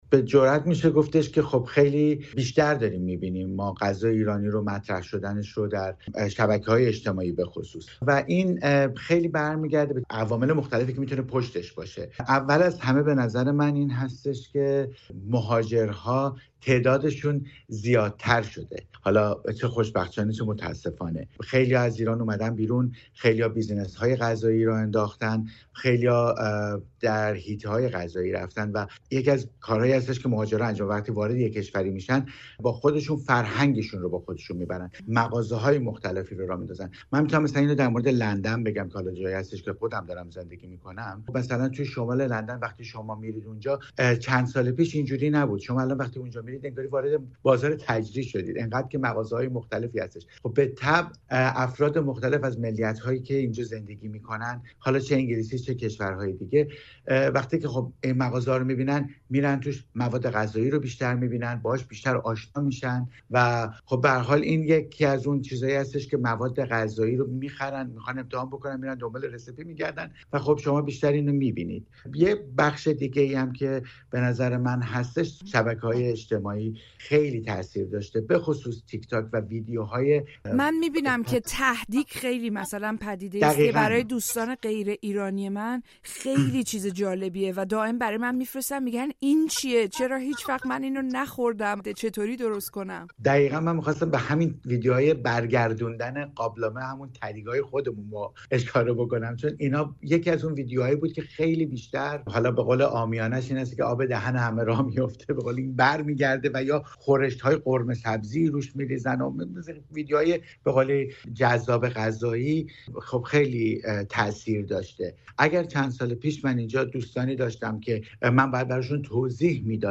«طعم‌های ایرانی در فرنگ» در گفت‌وگو